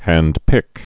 (hăndpĭk)